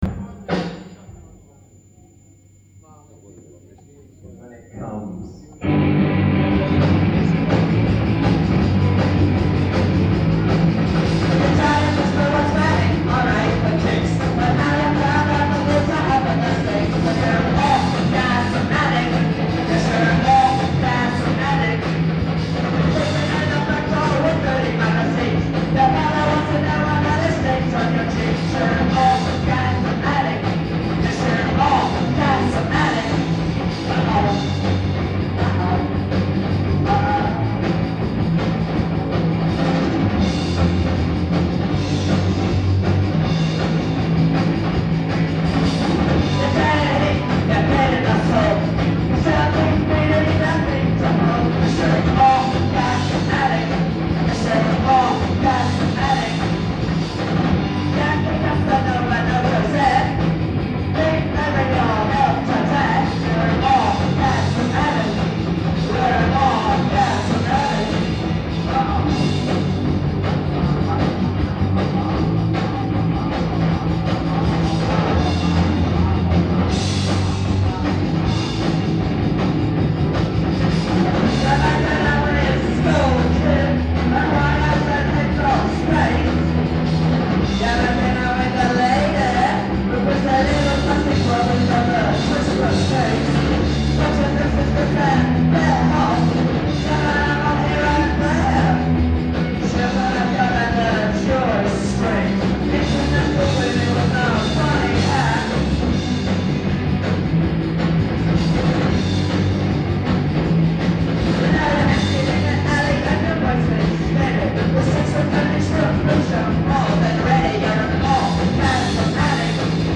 with British punk